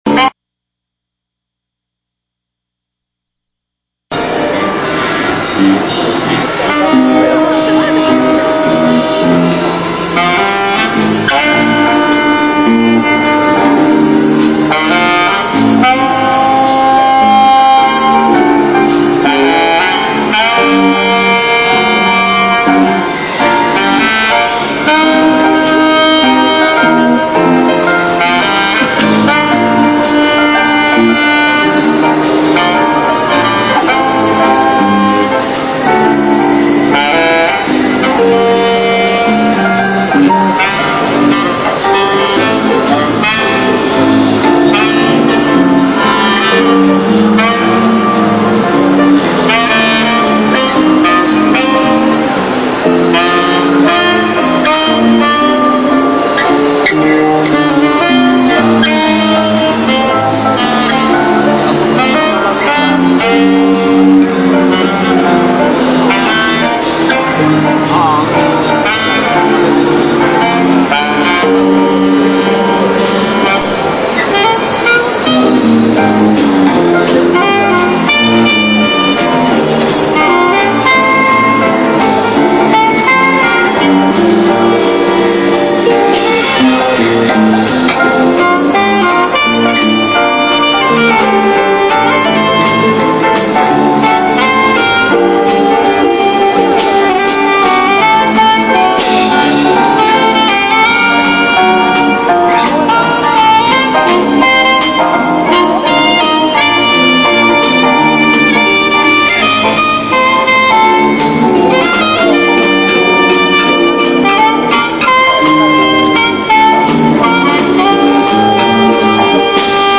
出来的には、練習回数の割にはなんとかそこそこ曲になったかなといったところでした。
実際の演奏・枯葉
９９．９．１２．SUN．仙台商工会議所前